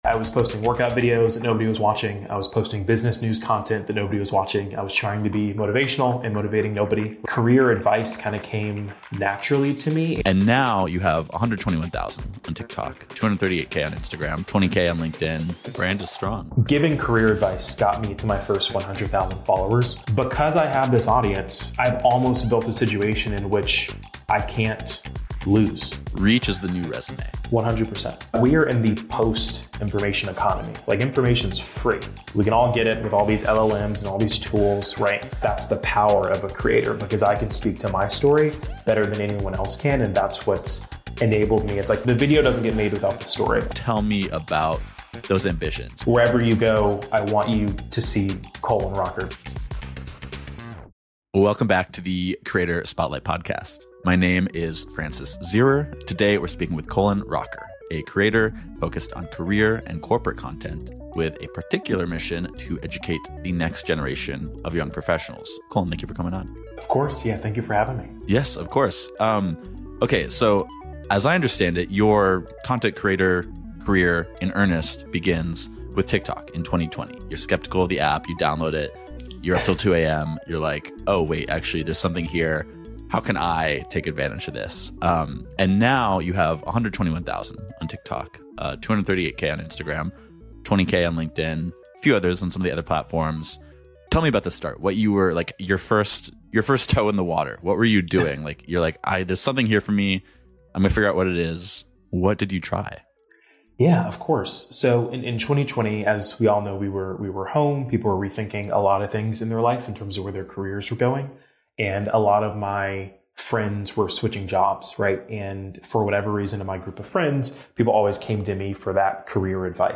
1 How HR Can Shape Business Strategy and Prove Its Impact (an Interview with Dave Ulrich) 52:16